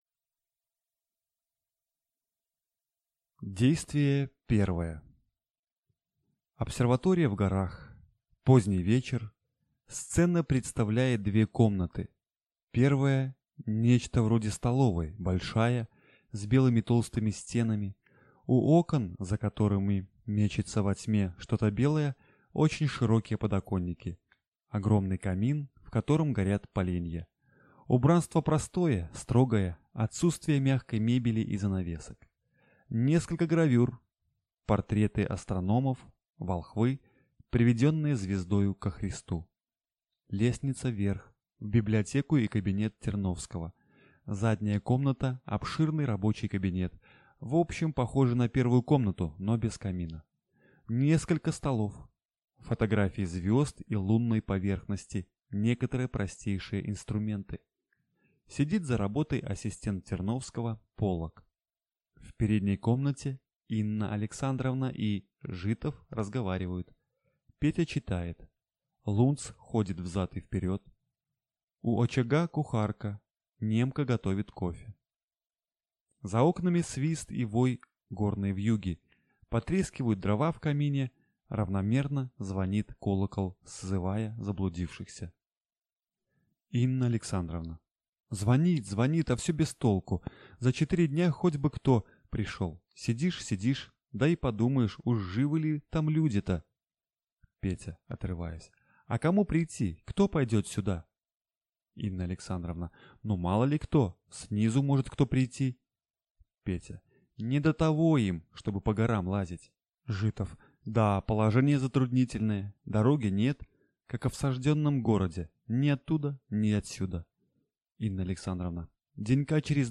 Аудиокнига К звездам | Библиотека аудиокниг